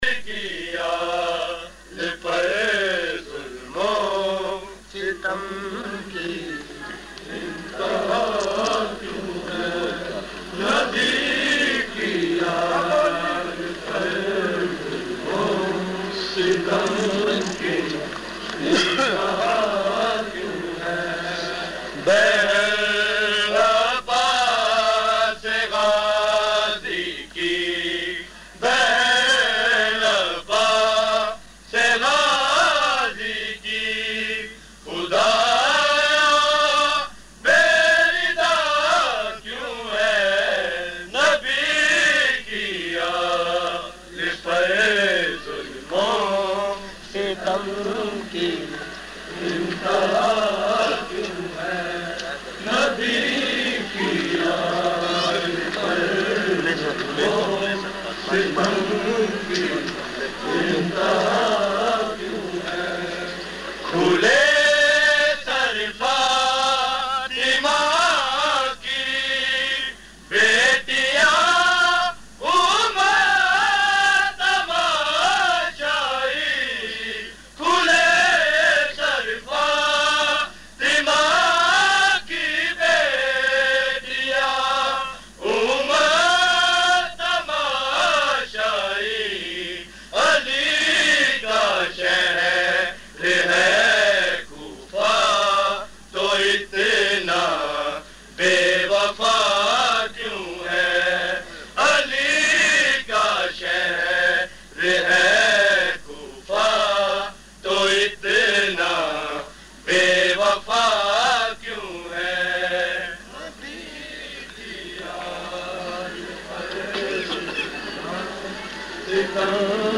Shahzada Aslam Party, Lahore
Recording Type: Live